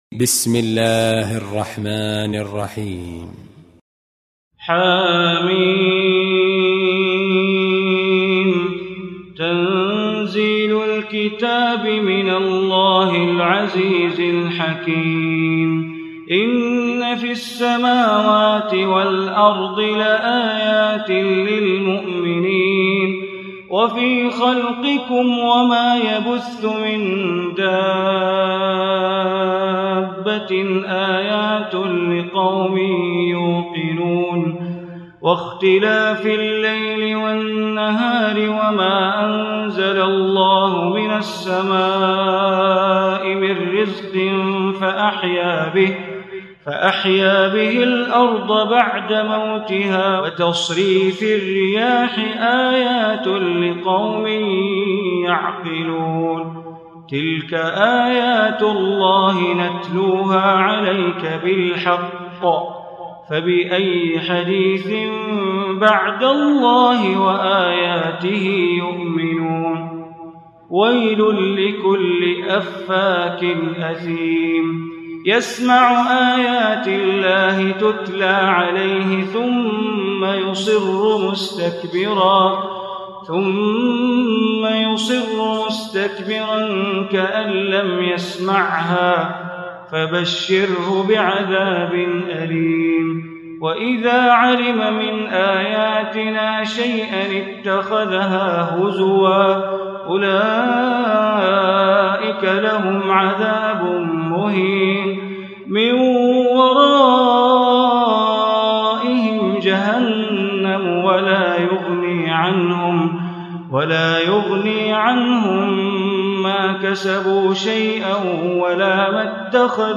Surah Jathiyah Recitation by Sheikh Bandar Baleela
Surah Al-Jathiyah, listen online mp3 tilawat / recitation in Arabic recited by Imam e Kaaba Sheikh Bandar Baleela.